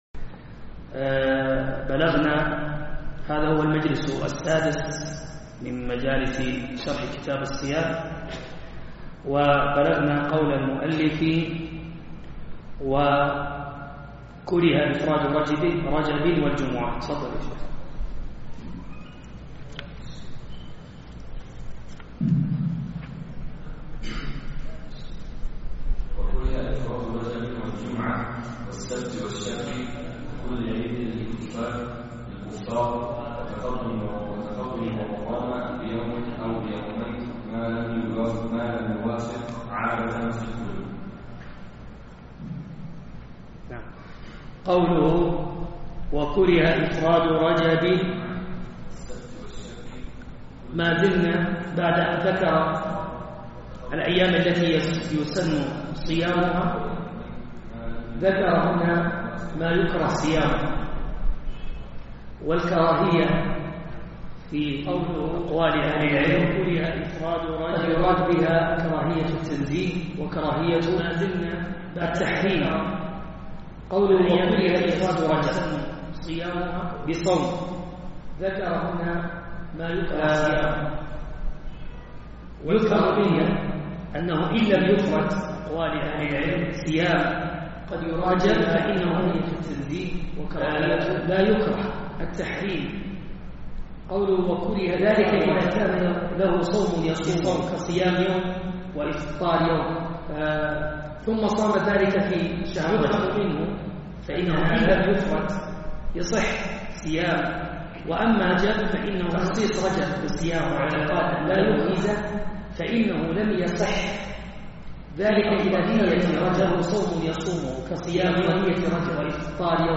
دروس وسلاسل